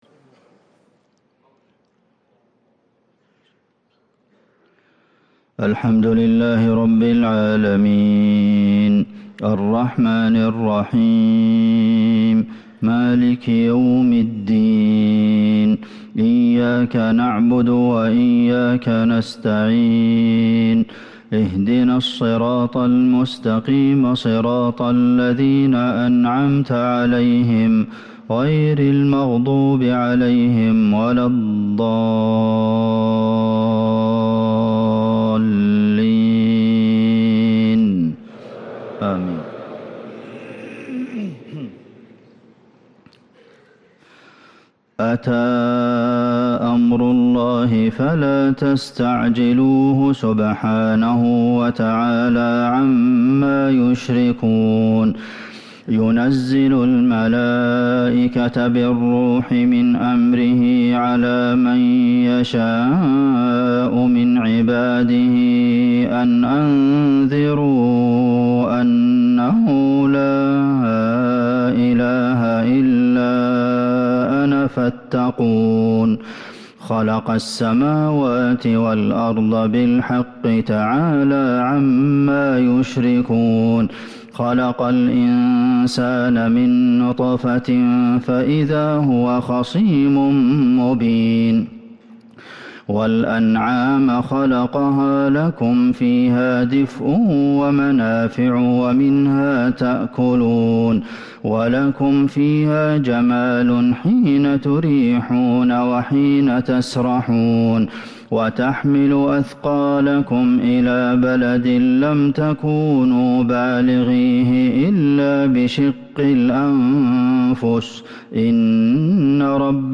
صلاة الفجر للقارئ عبدالمحسن القاسم 11 جمادي الأول 1445 هـ